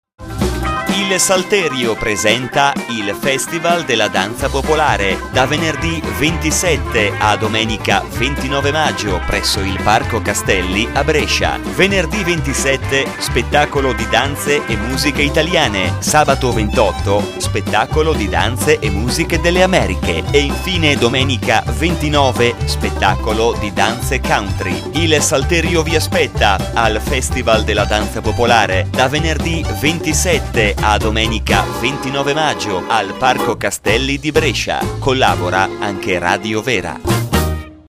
Volantino evento Locandina evento Spot da Radio Vera Con il patrocinio e la collaborazione di: Con la collaborazione di: Con il contributo di: